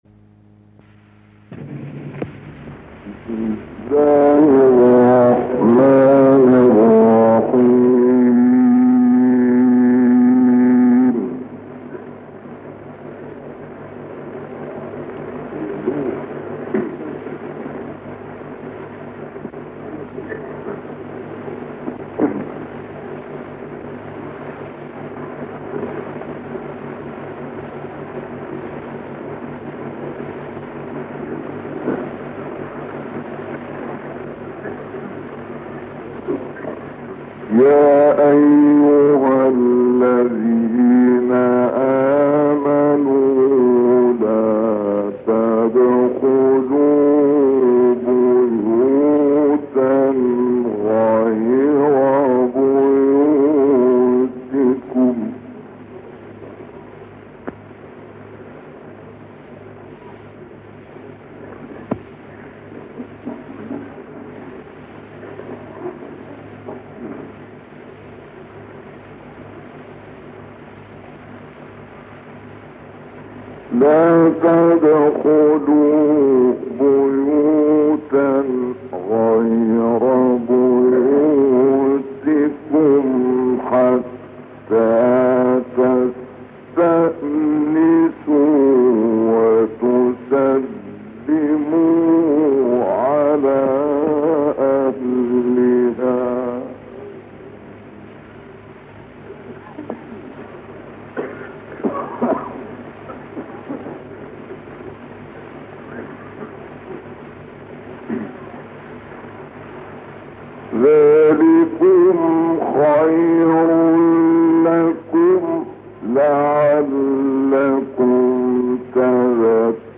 تلاوت مجلسی «شعشاعی» در حرم امام کاظم(ع)
گروه فعالیت‌های قرآنی: تلاوت عبدالفتاح شعشاعی از آیات مبارکه سوره نور اجرا شده در حرم حضرت امام موسی کاظم(ع) ارائه می‌شود.
این تلاوت در صحن حرم امام موسی کاظم(ع) و در سال 1950 میلادی اجرا شده و مدت زمان آن 30 دقیقه است.